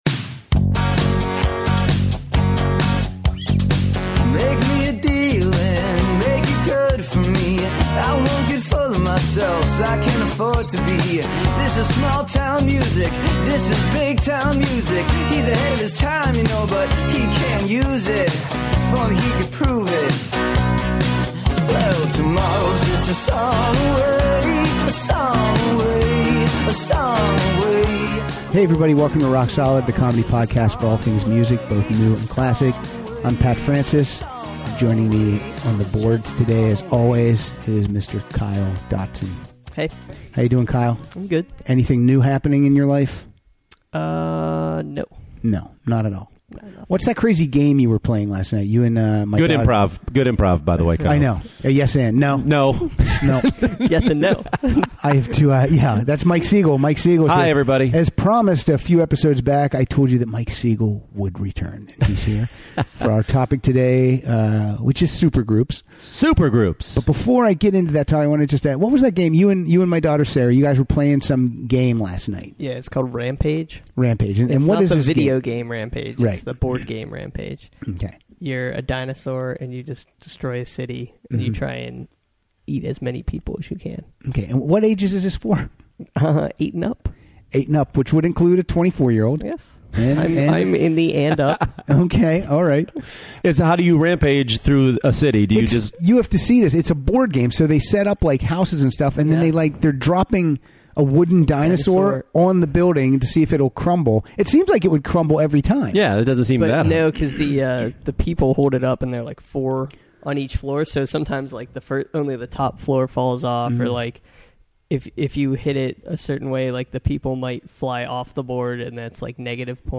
to play songs from the world's mightiest bands!